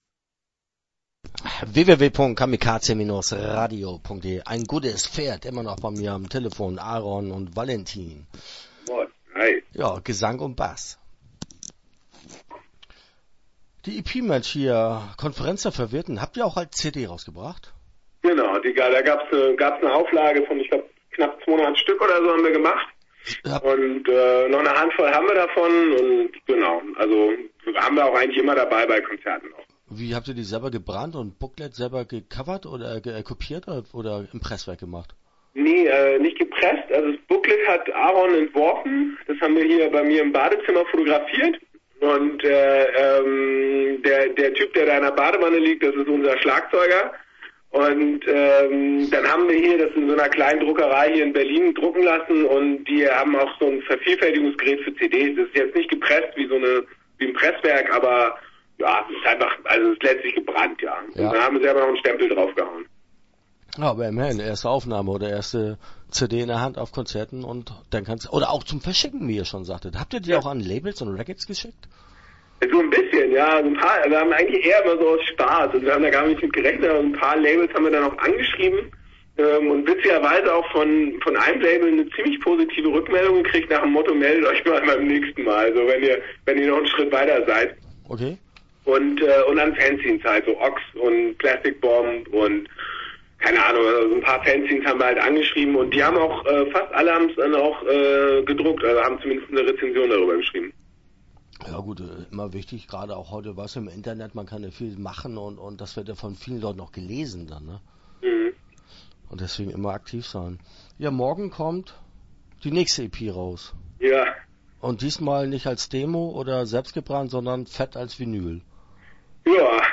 Ein Gutes Pferd - Interview Teil 1 (13:07)